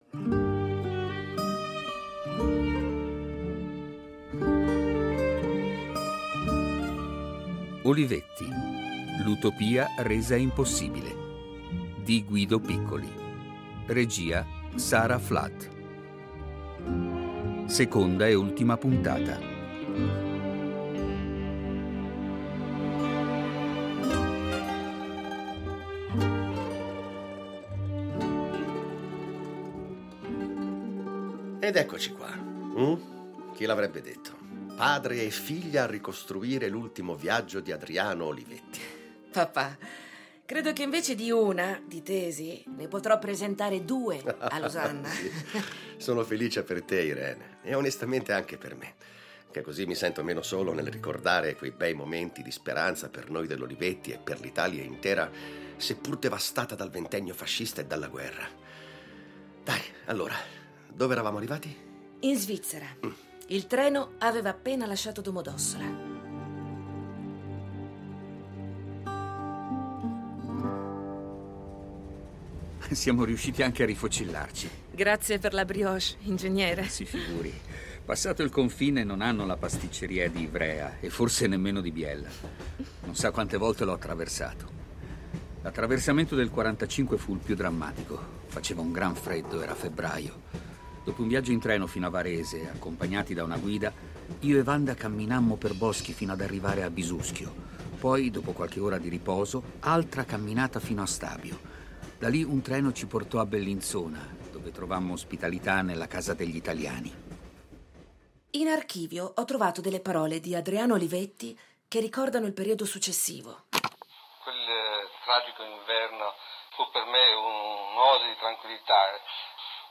radiodramma